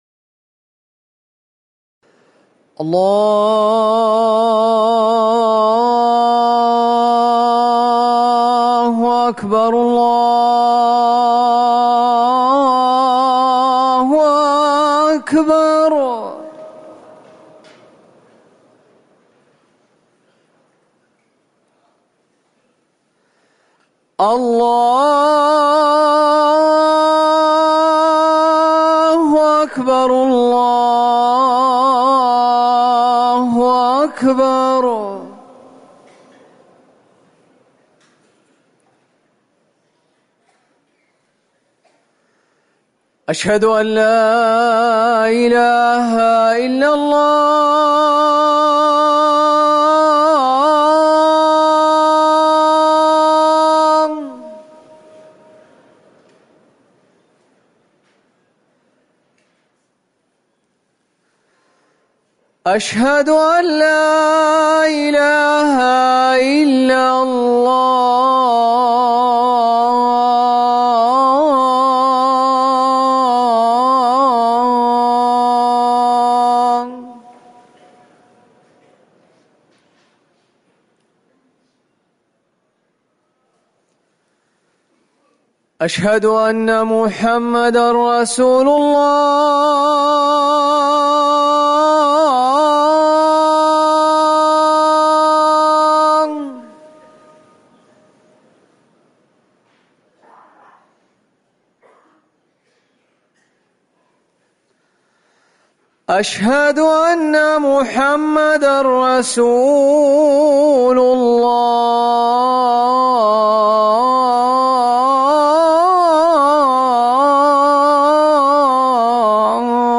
أذان العشاء - الموقع الرسمي لرئاسة الشؤون الدينية بالمسجد النبوي والمسجد الحرام
تاريخ النشر ٧ صفر ١٤٤١ هـ المكان: المسجد النبوي الشيخ